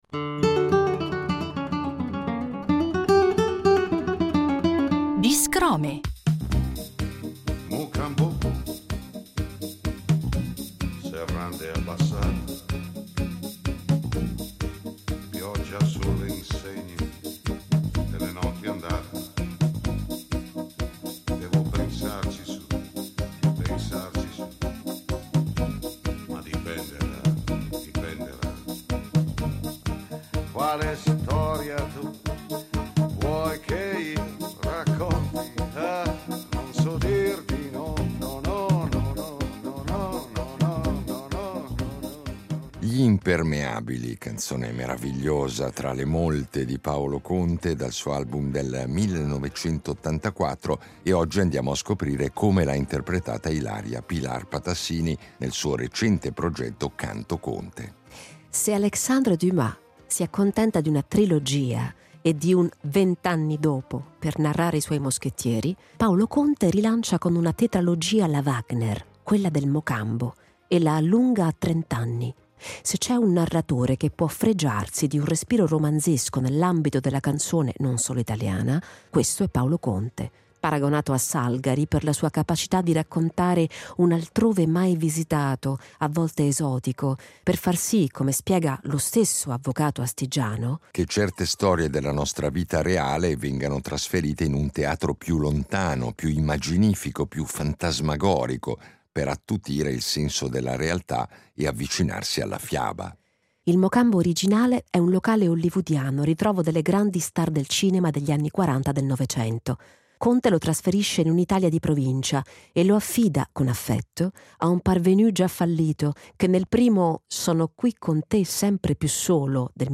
Un’artista che il nostro pubblico conosce bene perché ha collaborato spesso e volentieri con noi, e che negli anni ha sviluppato un percorso artistico eclettico, caratterizzato da una voce raffinata e da una predilezione per progetti che fondono diverse discipline, come il teatro e la poesia.
In questa serie di Biscrome ci presenta con parole sue le molte canzoni che ci ha regalato per programmi diversi, oltre al suo recente progetto discografico dal titolo eloquente, Canto Conte, che l’artista descrive non solo come una sfida interpretativa, ma come un vero e proprio «bisogno», «una necessità intima personale, oltre che artistica».